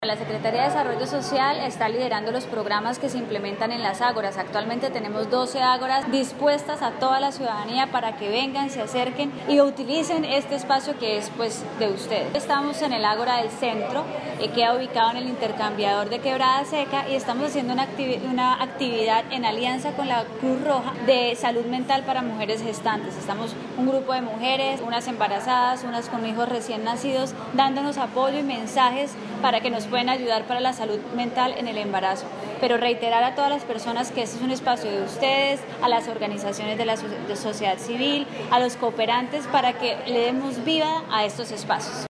Natalia Durán, secretaria de Desarrollo Social
Natalia-Duran-secretaria-de-Desarrollo-Social.mp3